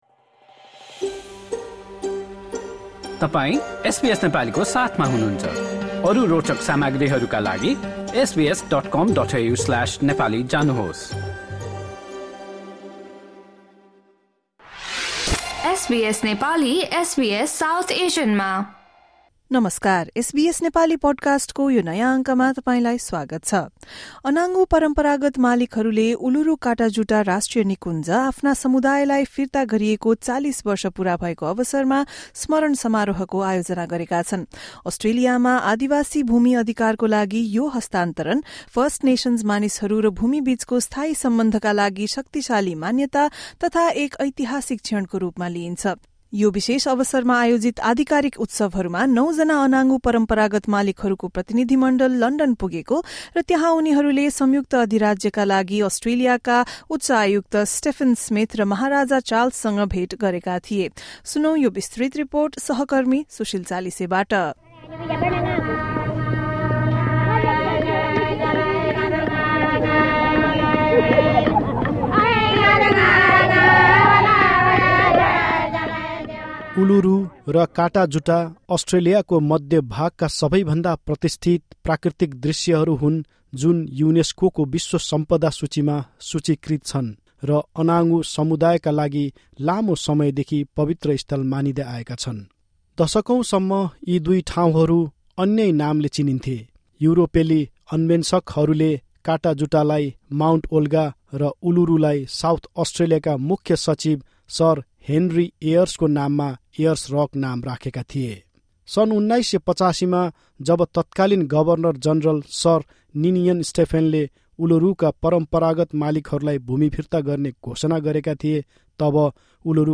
सुनौँ एक रिपोर्ट